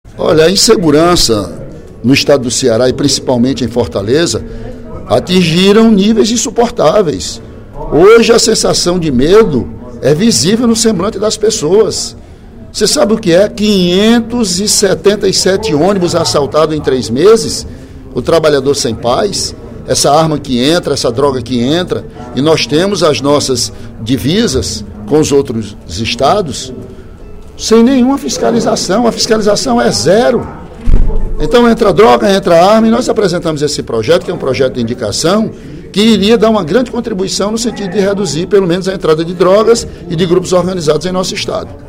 Durante o primeiro expediente da sessão plenária desta quinta-feira (25/04), o deputado Ely Aguiar criticou a violência crescente em Fortaleza e no Ceará.